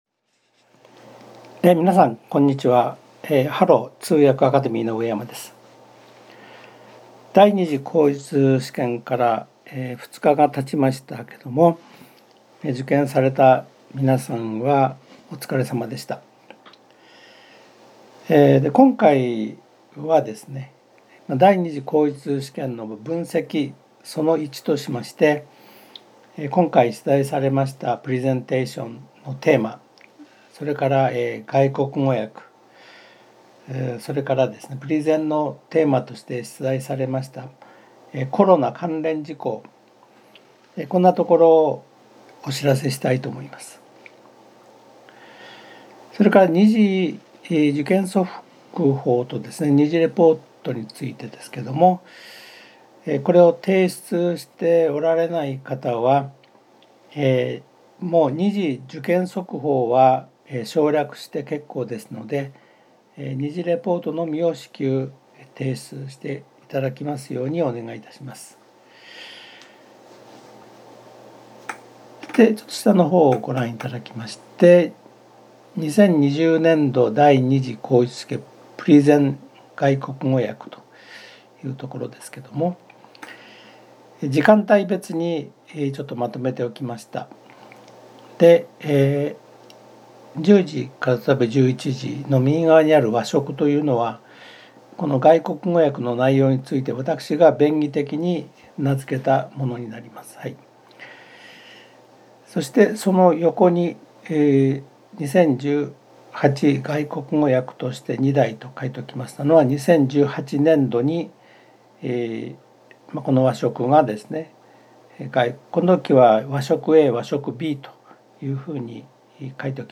●＜音声説明ガイド＞